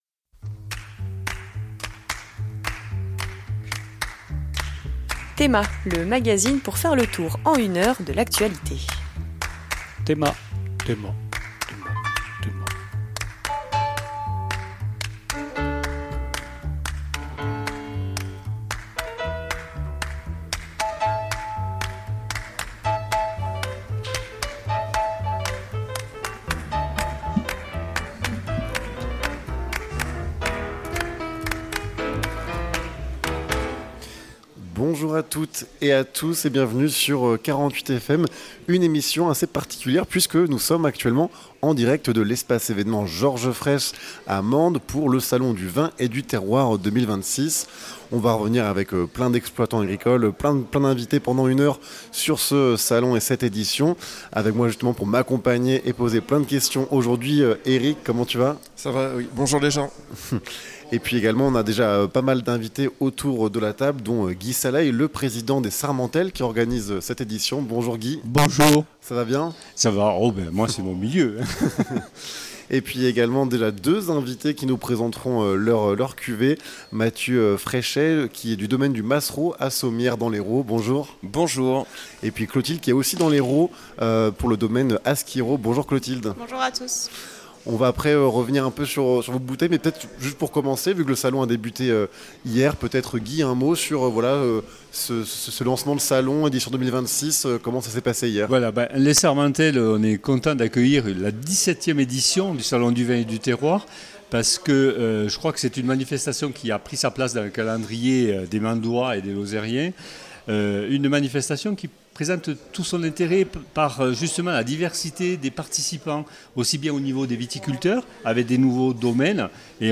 En direct de l’espace évènement Georges Frêche.